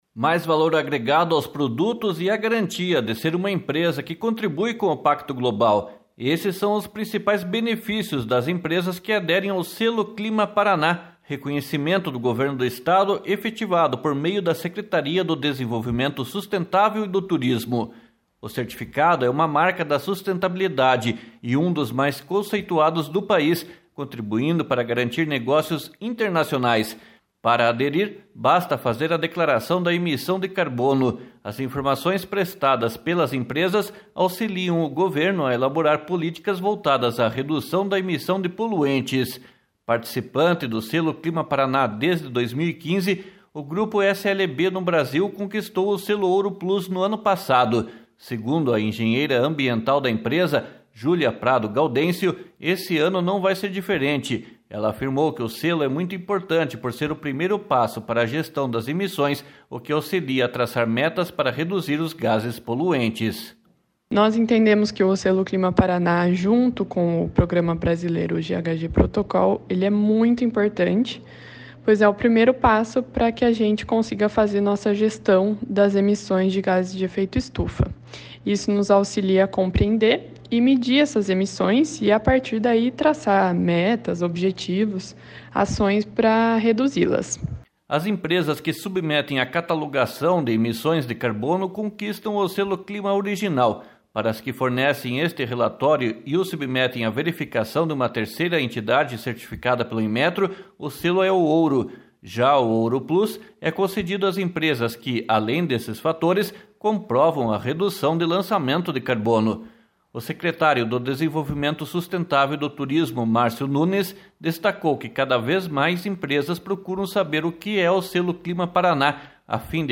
O secretário do Desenvolvimento Sustentável e do Turismo, Márcio Nunes, destacou que cada vez mais empresas procuram saber o que é o Selo Clima Paraná, a fim de garantir a marca da sustentabilidade.